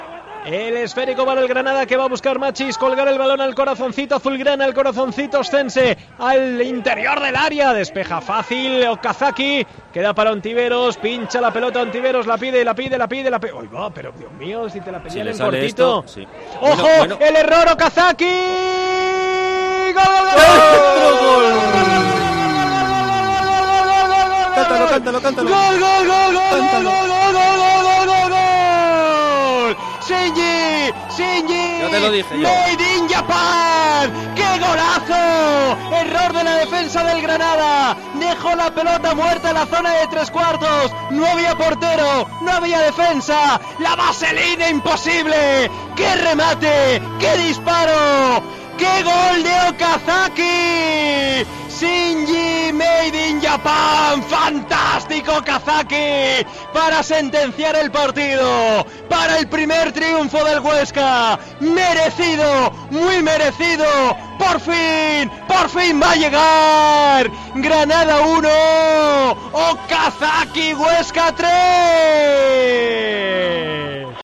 Narración Gol de Okazaki / Granada 1-3 Huesca